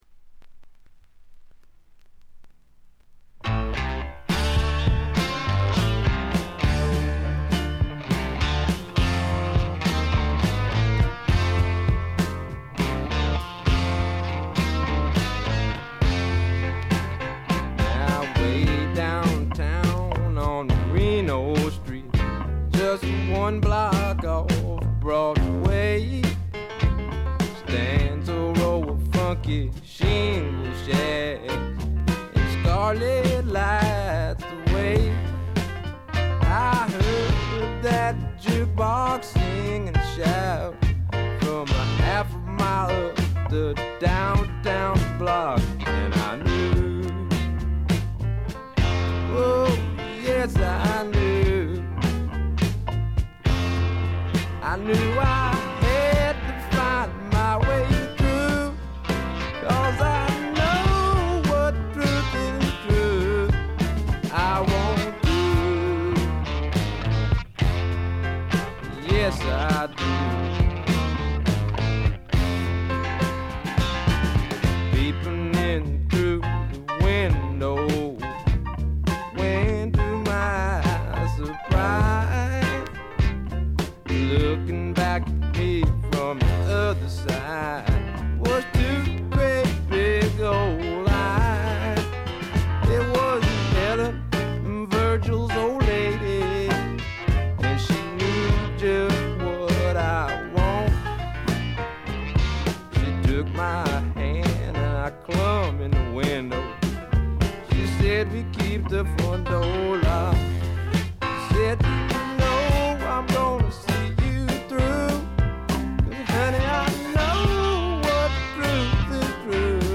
わずかに軽微なチリプチ。
まさしくスワンプロックの真骨頂。
それにしてもこのベースの音は尋常ではない凄みがあります。
試聴曲は現品からの取り込み音源です。
Vocal, Guitar, Keyboards